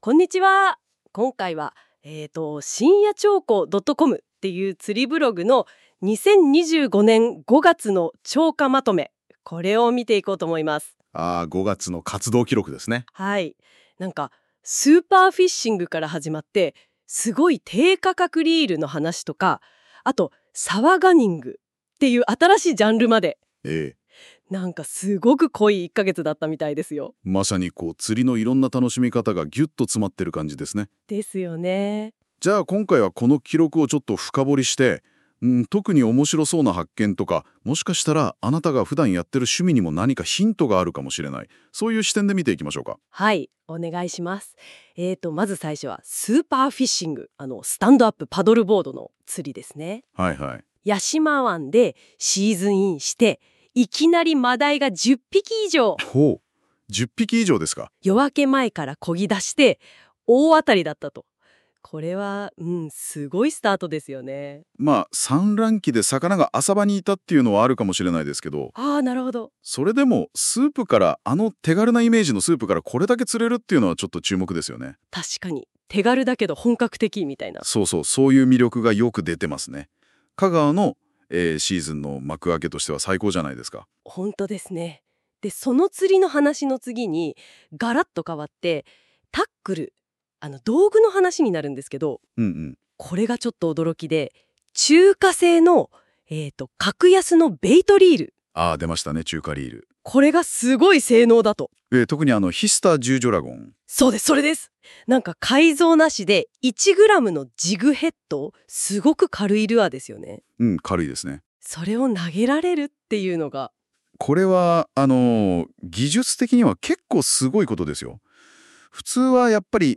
NotebookLM (Googleが提供するAIを活用した情報整理・リサーチアシスタントツール) を最大限に活用し、今回のブログ記事から音声概要も提供することにしました。
AIによる音声には、まだ専門用語の学習や読解力の強化が必要な部分もあります。